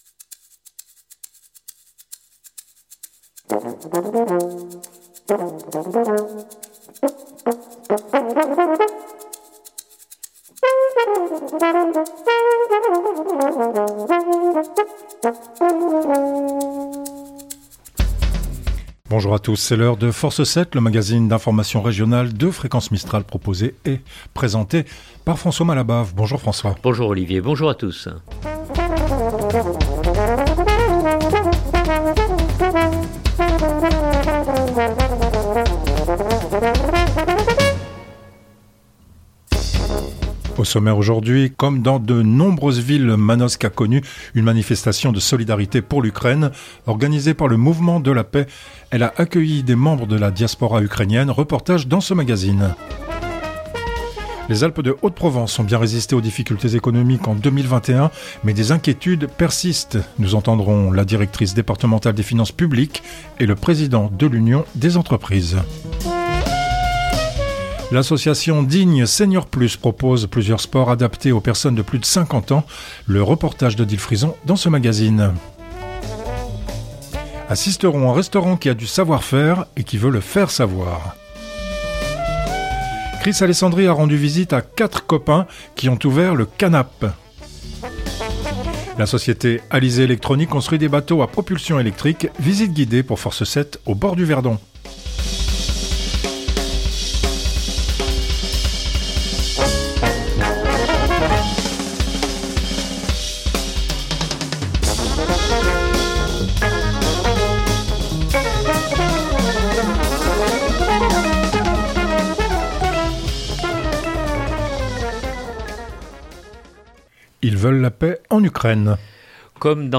Reportage dans ce magazine. -Les Alpes de Haute-Provence ont bien résisté aux difficultés économiques en 2021 mais des inquiétudes persistent.